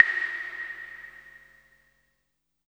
81 CLAVE  -L.wav